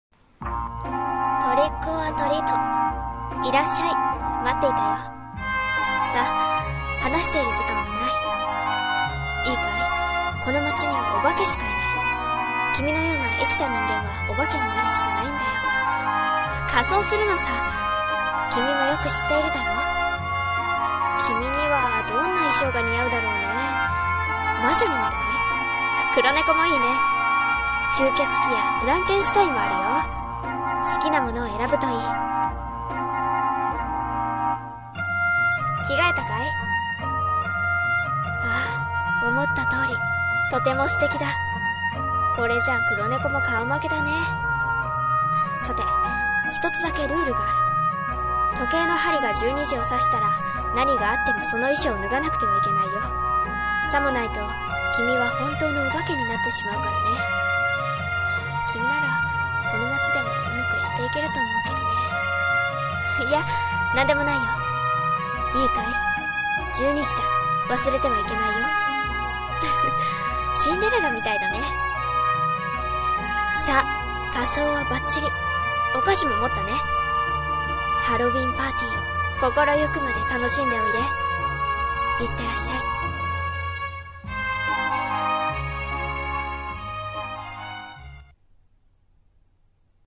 声劇「ようこそハロウィンタウンへ